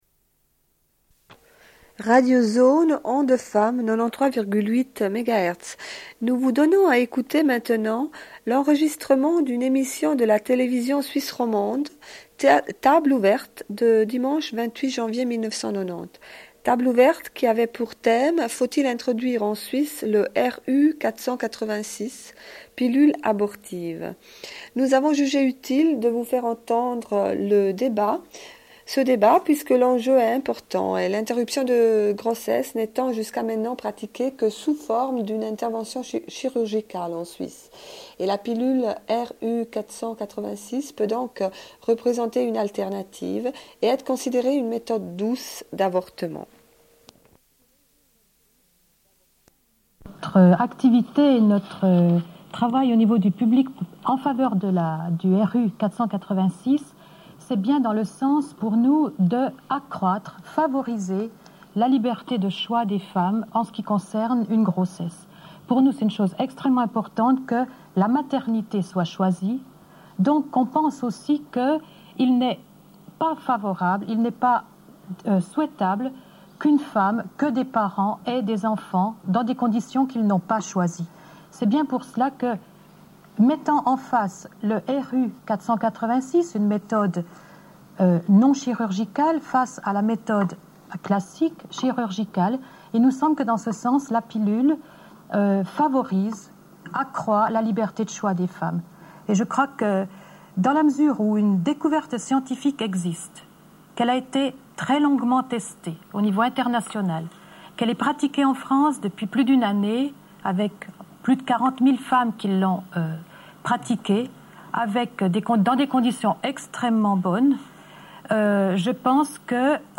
Une cassette audio, face A31:22
Émission autour de la pilule RU 86, ou la mifépristone. Retransmission d'un débat sur la Télévision Suisse Romande.